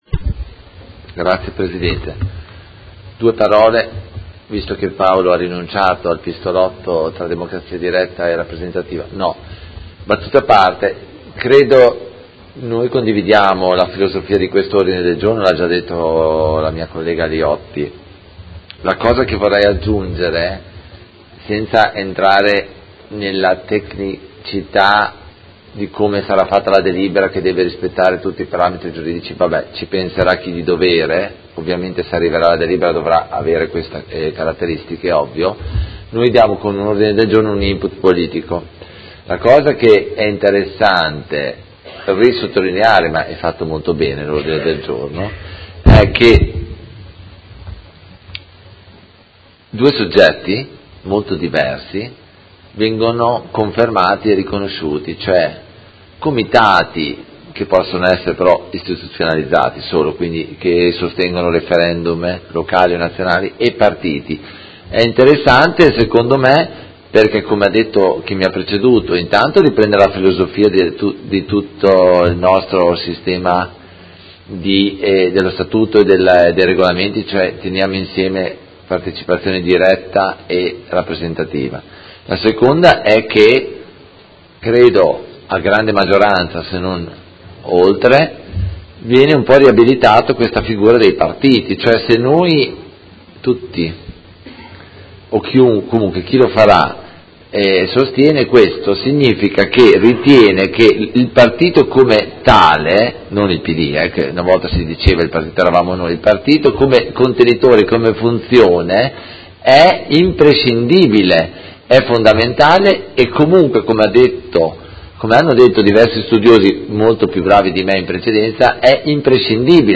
Seduta del 10/05/2018 Mozione presentata dal Gruppo Consiliare Art.1-MDP/Per me Modena avente per oggetto: Favoriree facilitare la partecipazione alla vita politica e democratica delle cittadine e dei cittadini modenesi